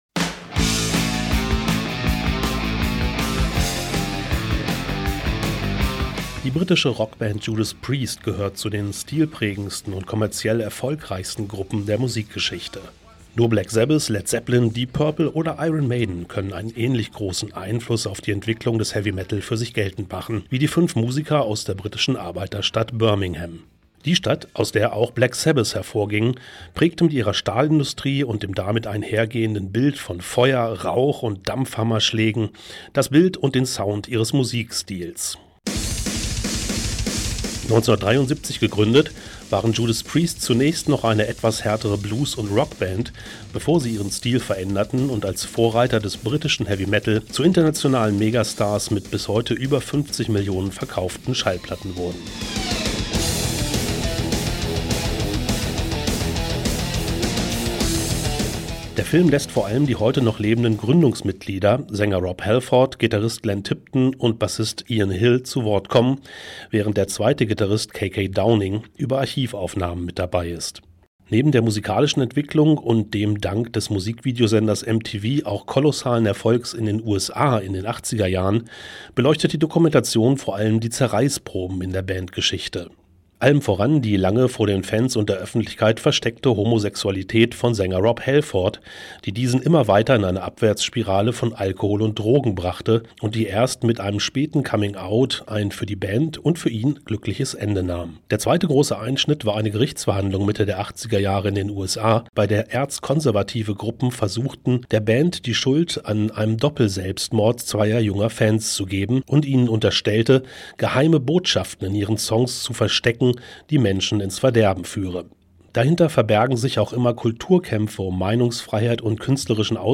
(Pressekonferenz zu „The Ballad of Judas Priest“, Berlin 15.02.2026)
(Dieser Beitrag erschien zuerst als Radiobeitrag in der Sendung „Filmriss – Das Berlinale-Magazin“, einem gemeinsamen Projekt der norddeutschen Bürgersender Kiel FM, Lübeck FM, Westküste FM, Tide Hamburg, Radio Leinewelle und Oldenburg Eins.)